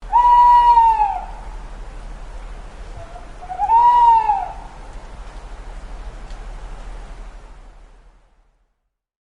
Loud Owl Call
Loud Owl Call is a free sfx sound effect available for download in MP3 format.
yt_cd60UMKL5Hw_loud_owl_call.mp3